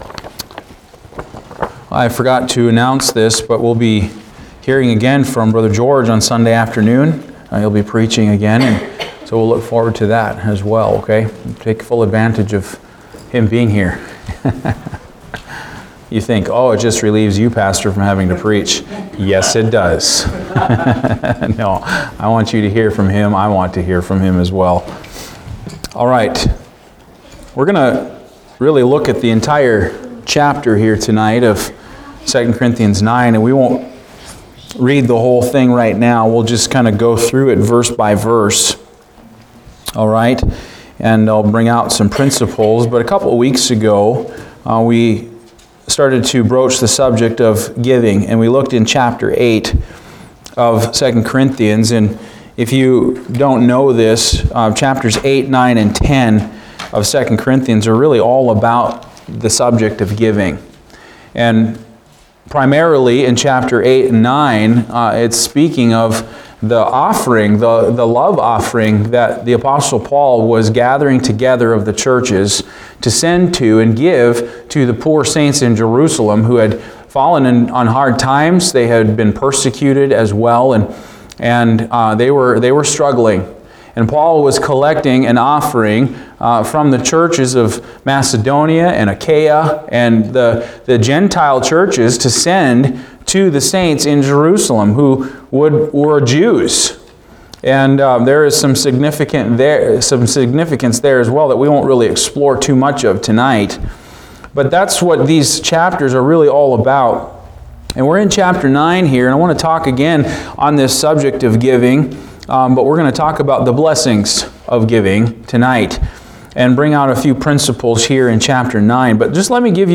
Passage: II Corinthians 9:1-15 Service Type: Wednesday Evening